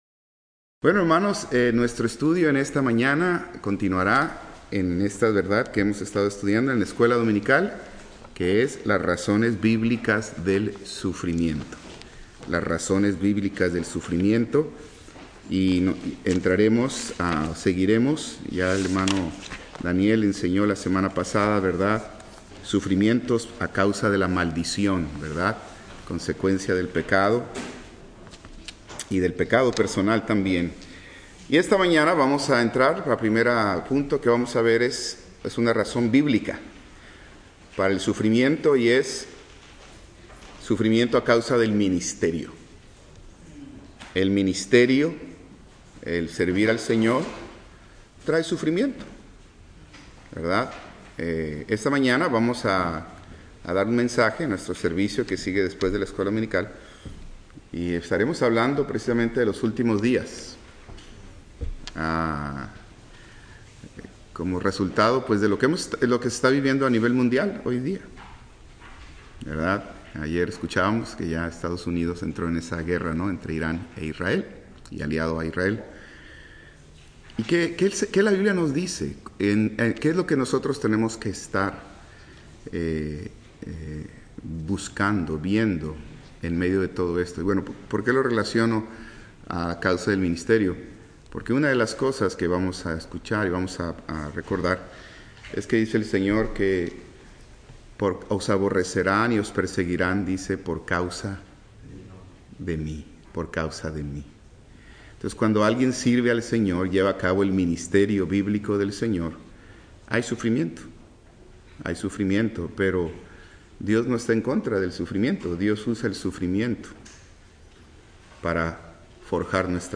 Escuela Dominical